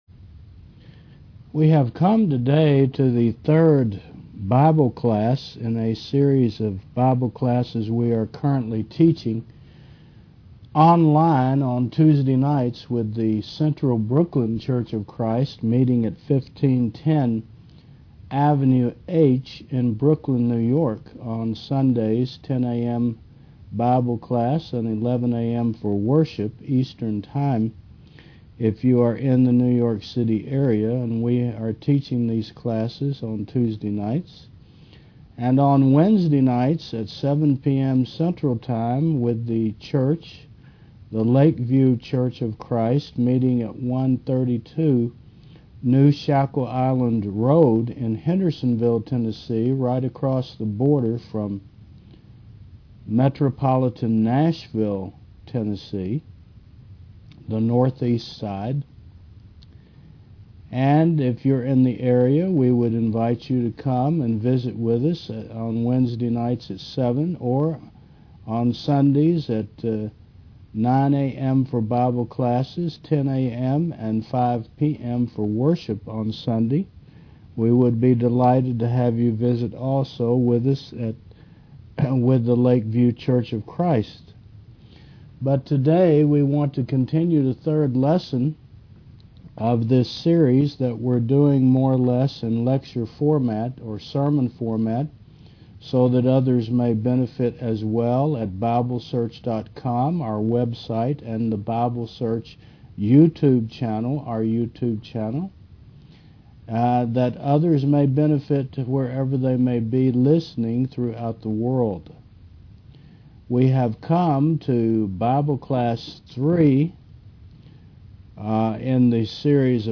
Romans Classes